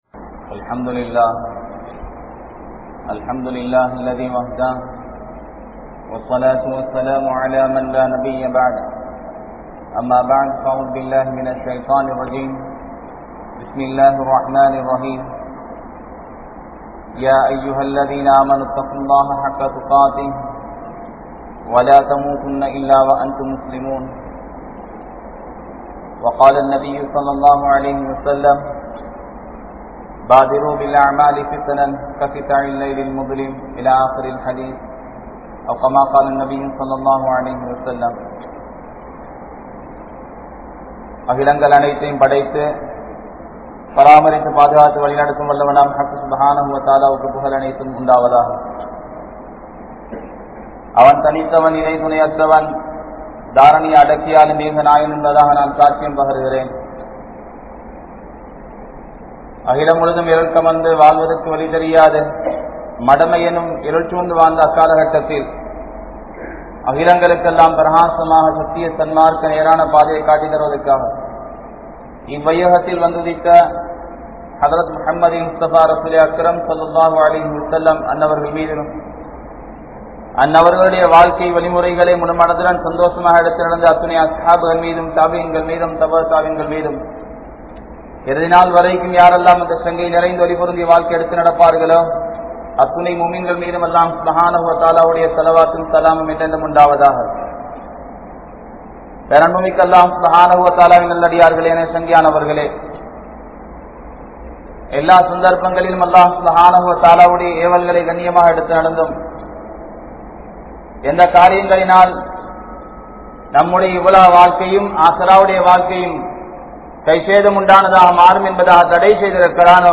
Allah`vukkaaha Vaalungal (அல்லாஹ்வுக்காக வாழுங்கள்) | Audio Bayans | All Ceylon Muslim Youth Community | Addalaichenai